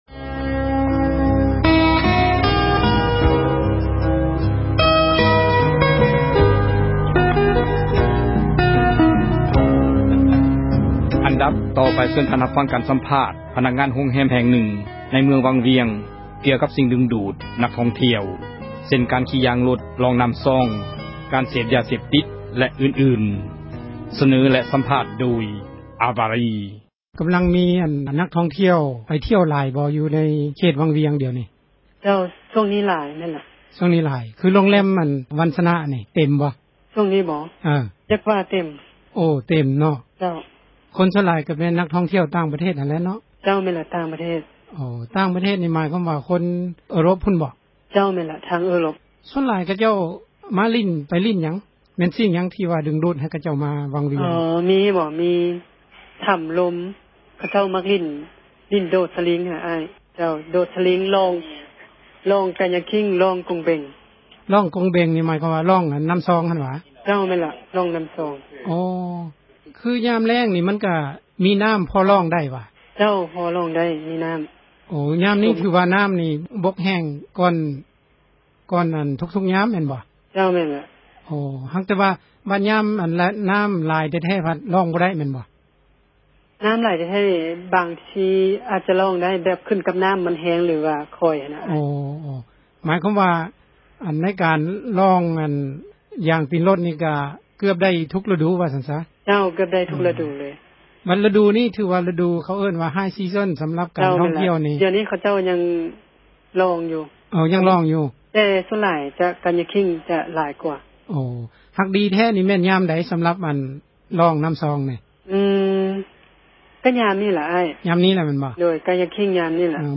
ເຊີນທ່ານຮັບຟັງ ການສັມພາດ ພນັກງານ ໂຮງແຮມ ແຫ່ງນຶ່ງ ໃນເມືອງວັງວຽງ ກ່ຽວກັບ ສິ່ງດຶງດູດ ນັກທ່ອງທ່ຽວ ເຊັ່ນການ ຂີ່ຢາງຣົດ ລ່ອງນໍ້າຊອງ ການເສບ ຢາເສບຕິດ ແລະ ອື່ນໆ.